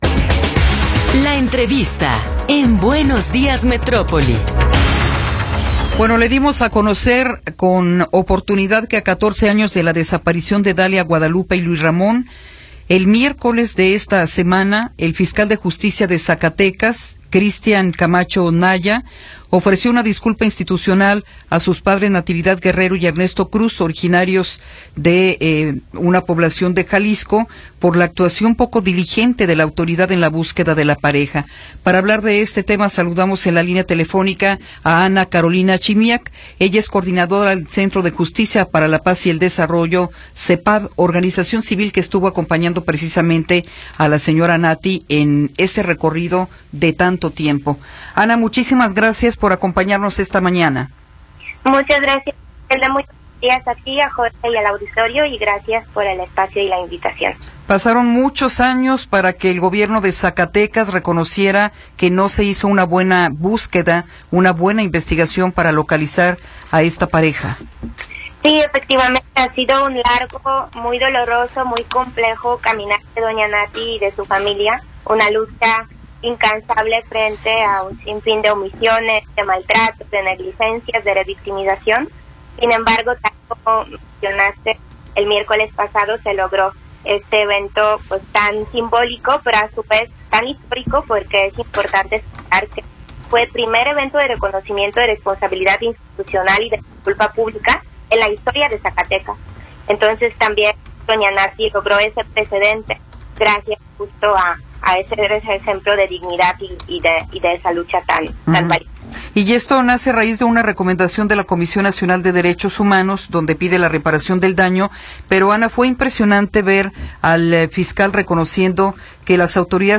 entrevista-5.m4a